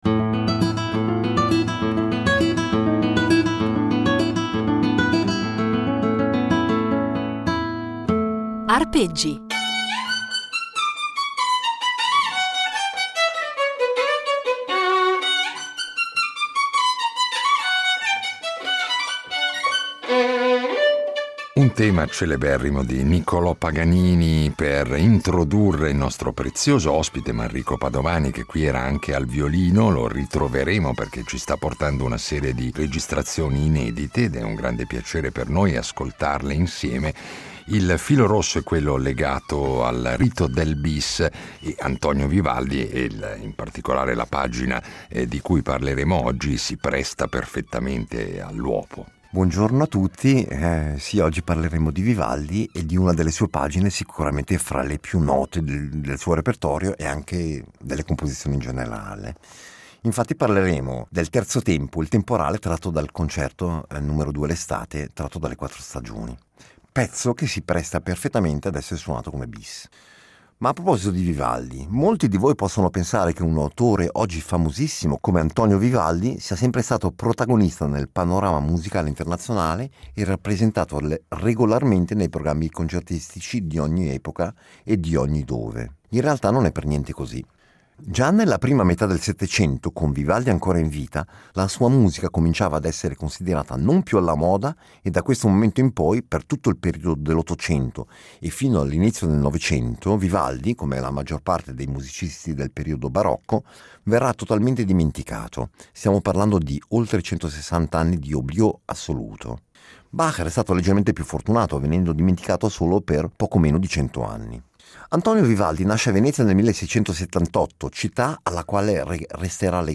violinista svizzero